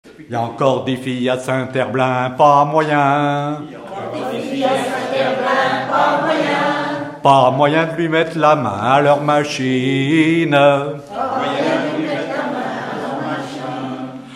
Mémoires et Patrimoines vivants - RaddO est une base de données d'archives iconographiques et sonores.
Genre énumérative
Catégorie Pièce musicale inédite